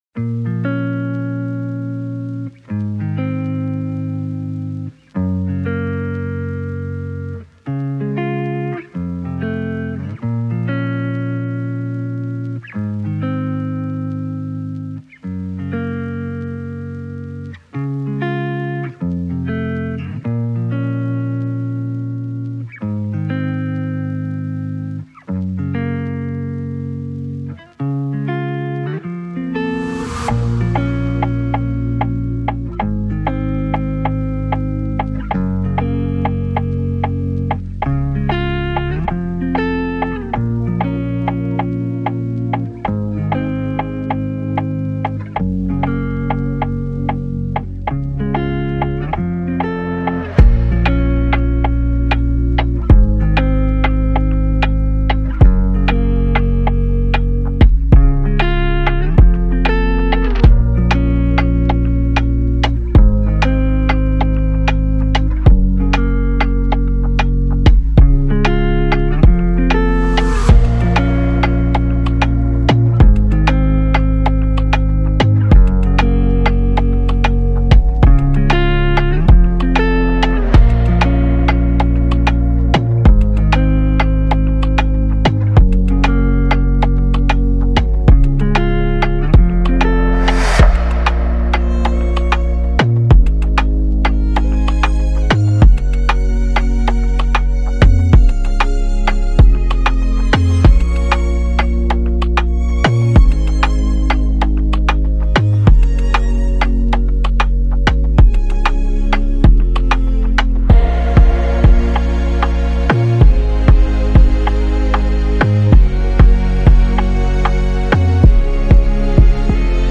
Latin Instrumental